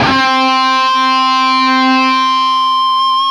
LEAD C 3 CUT.wav